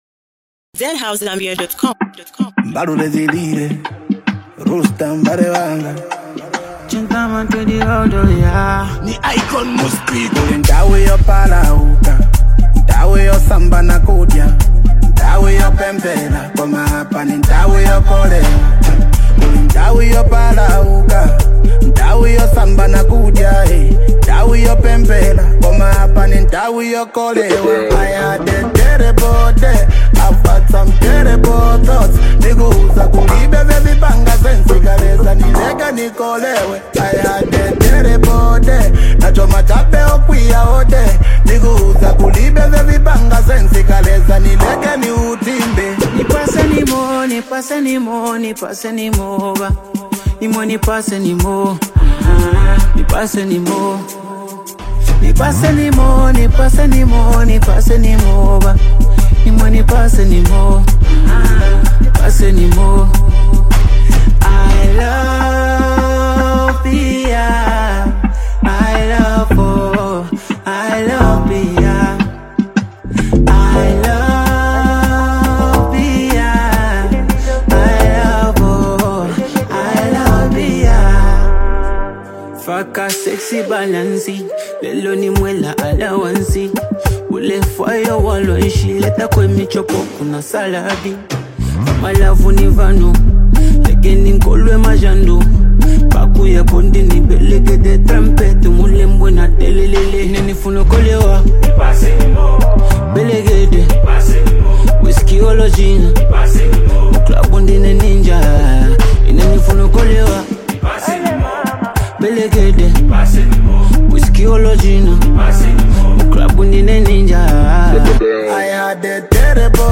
club anthem